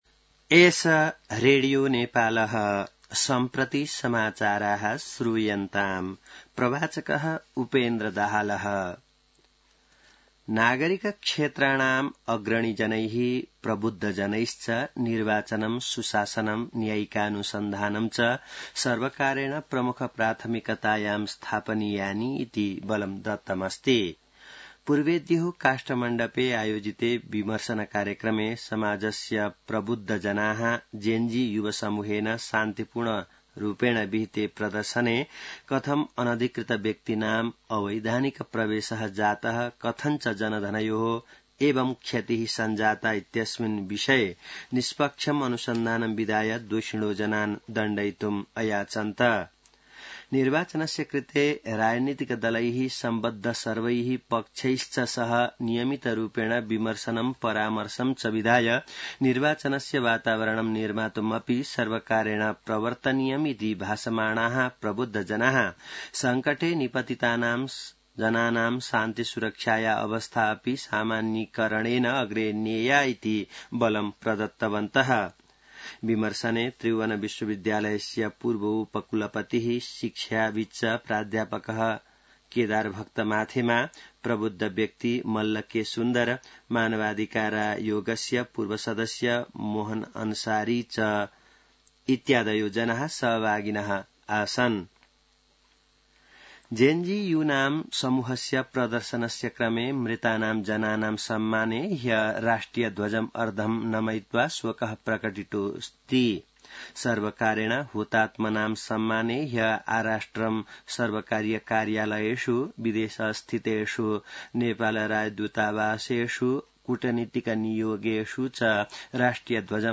संस्कृत समाचार : २ असोज , २०८२